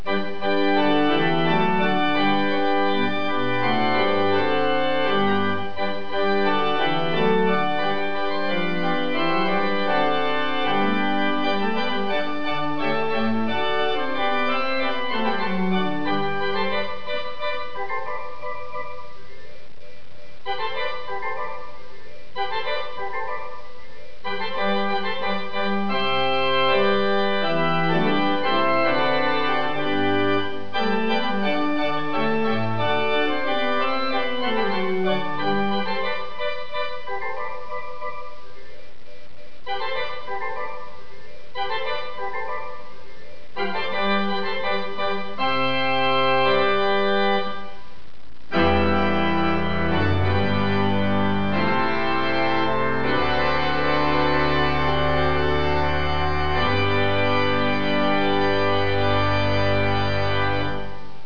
The organ illustrated, one of our larger installations, is in the First Christian Church in Burlington, NC, and is such an instrument. It consists of both true pipes-- nineteen ranks--and auxiliary electronically-operated speakers.
Click on the pipes to hear the organ
organist
organ1.wav